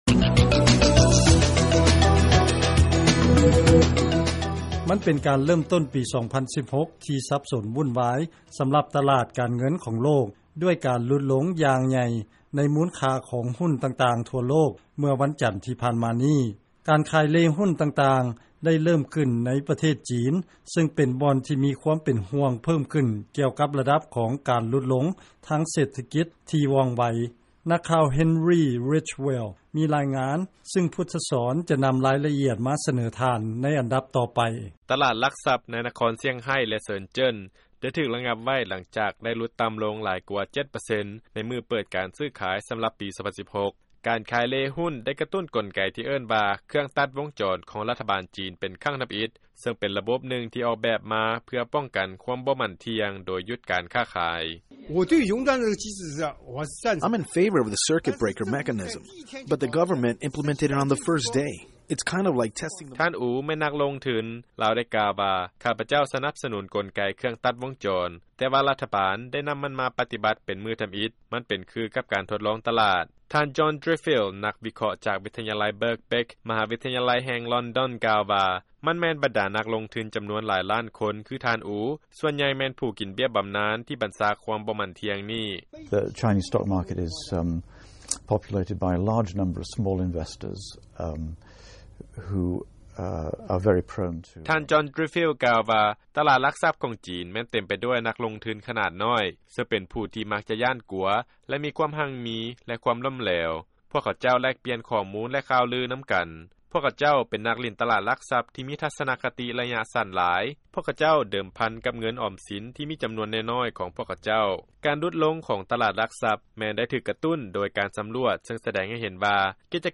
ຟັງລາຍງານ ຈີນ ລະງັບ ການຊື້ຂາຍ ໃນຕະຫຼາດ ຫຼັກຊັບ ໃນຂະນະທີ່ ຫຸ້ນຕ່າງໆ ມີລາຄາ ຕົກຕ່ຳລົງ